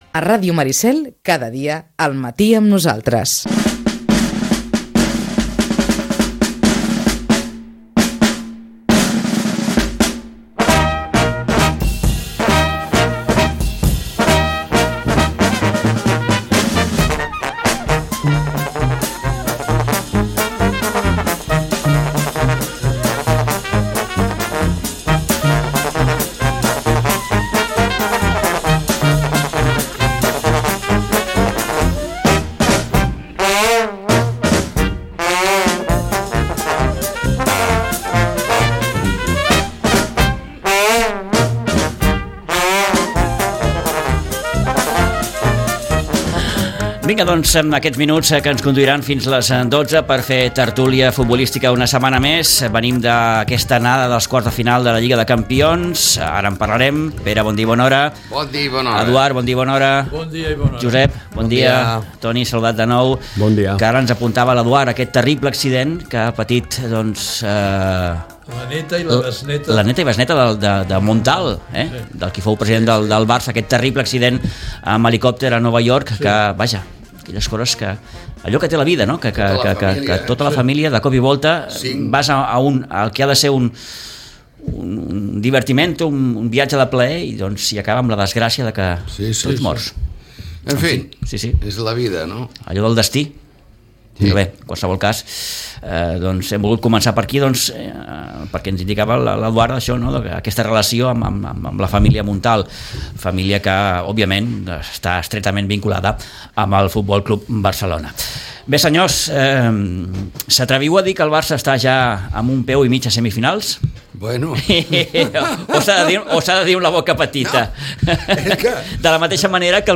La tertúlia esportiva